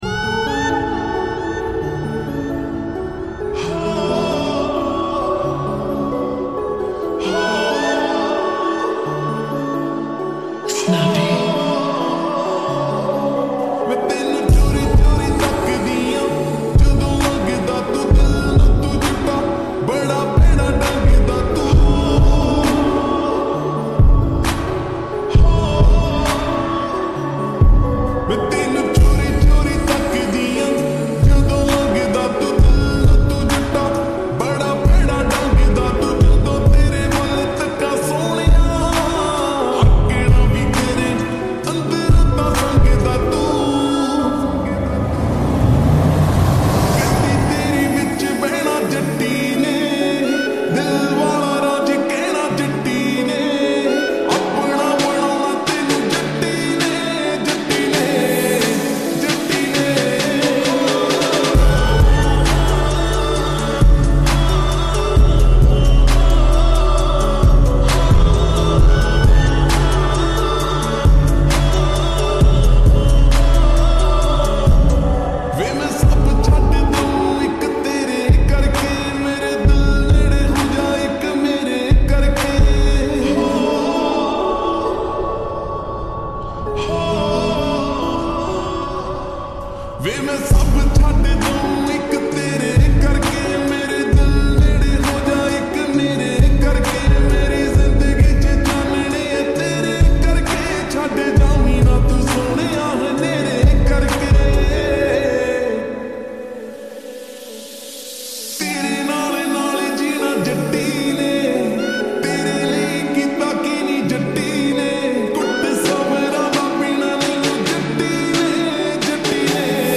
Full Song Slowed And Reverb